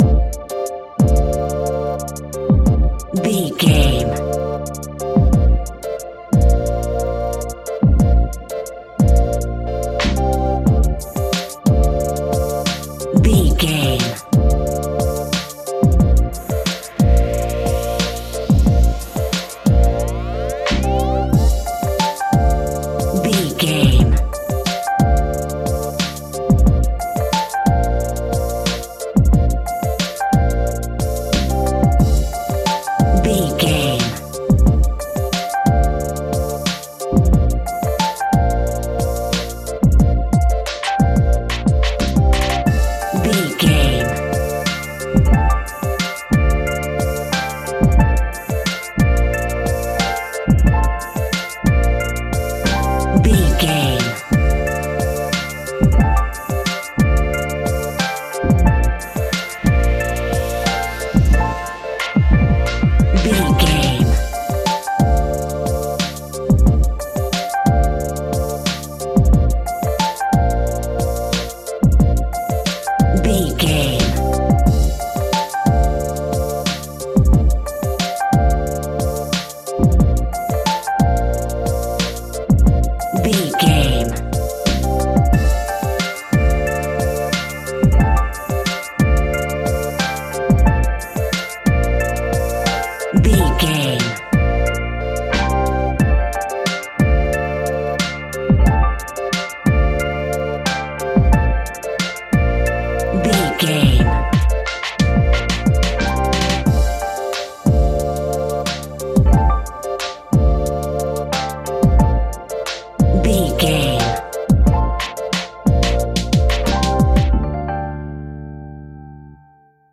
Ionian/Major
dreamy
relaxed
mellow
electric piano
synthesiser